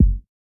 Waka Kick 2 (4).wav